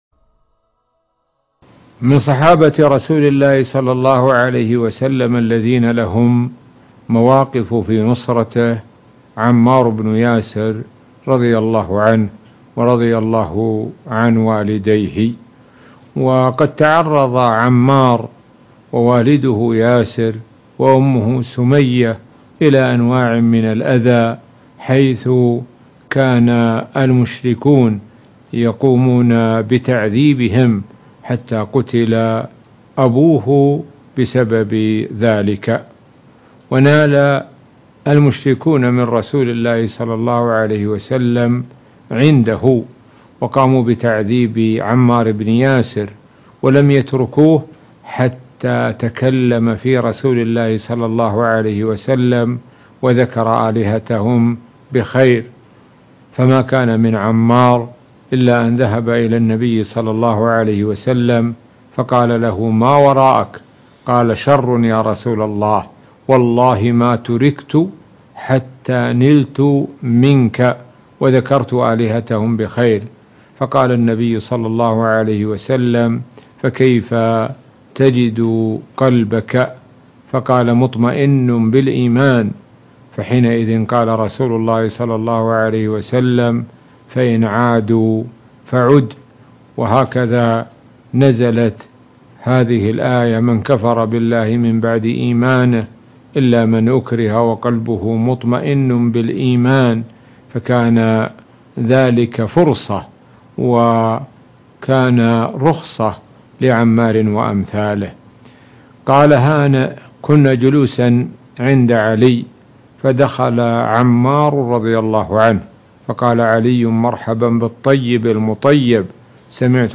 الأيام الخالية - فضيلة الشيخ د. سعد الشثري متحدثاً عن الصحابي الجليل عمار بن ياسر - الشيخ سعد بن ناصر الشثري